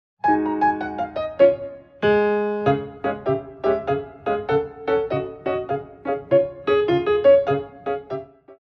4x8 - 6/8